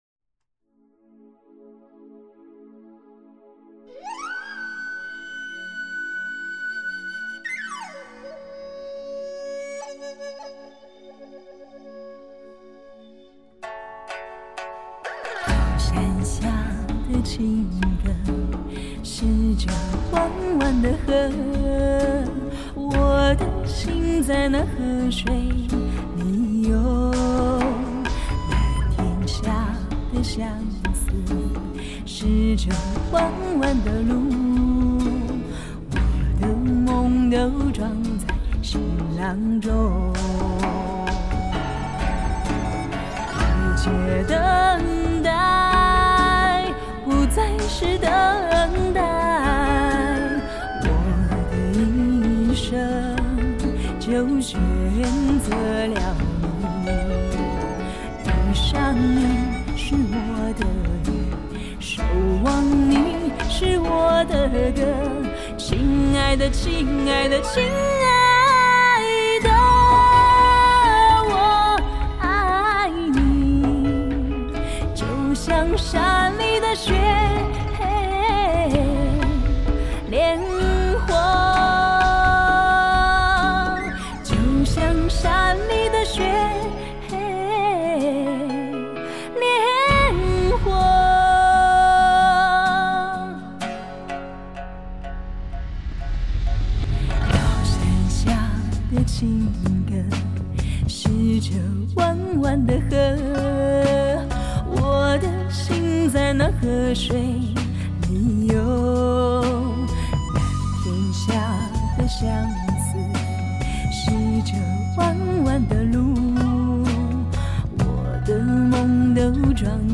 世界顶级录音设备全面满足听觉享受
天籁女声无法抗拒
而配乐中箫，琵琶，二胡，笛子，扬琴，木吉他的编排演奏，
呼之欲出的扬琴，急促嘹亮的笛子，奔跑的吉他，